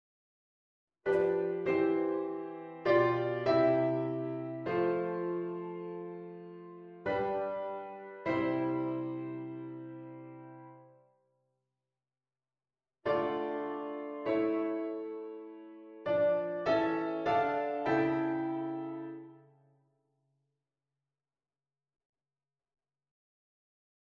syncopen